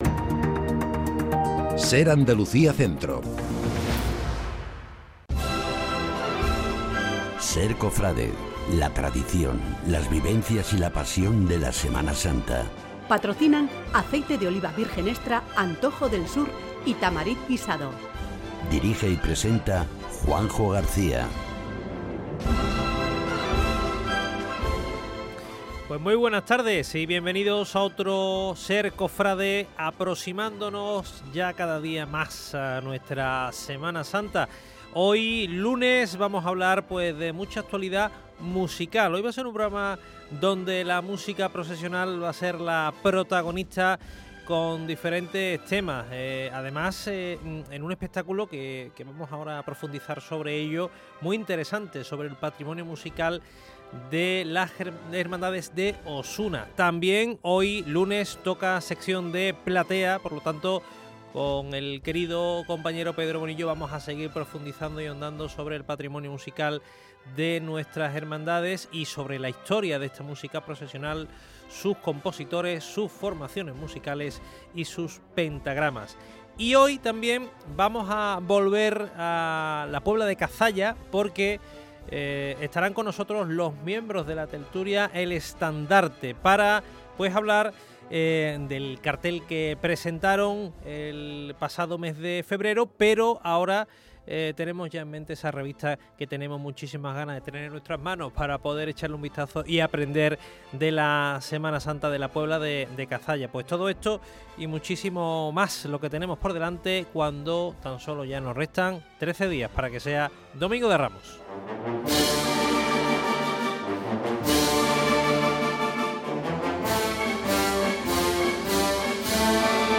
Un programa muy musical el que les hemos ofrecido, este lunes 16 de marzo, en SER Cofrade